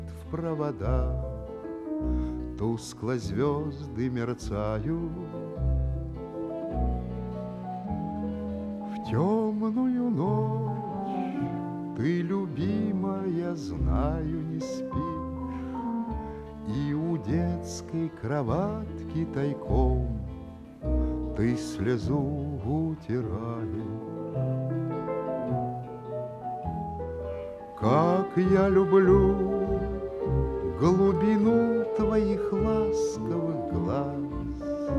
Жанр: Русская поп-музыка / Русский рок / Русские